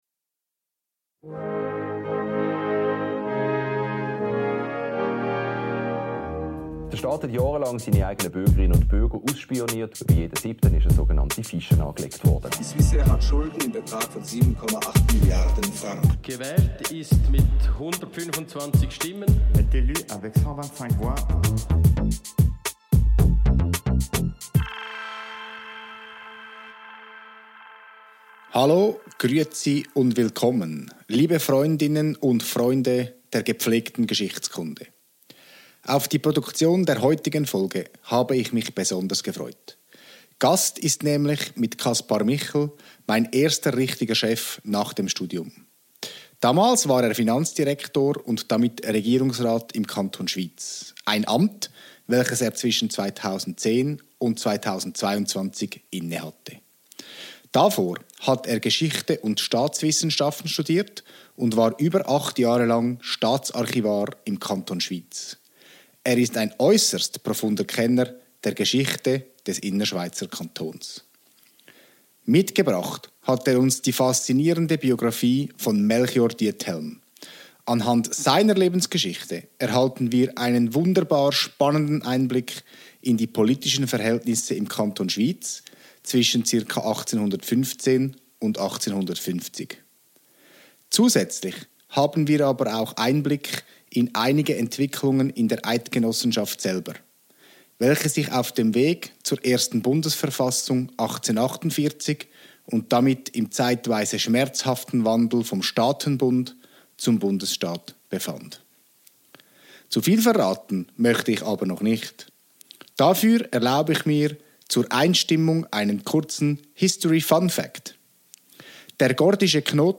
Gast in dieser Folge der Zeitgenossenschaft ist Kaspar Michel, er war 2016 nach dem Studium mein erster richtiger Chef.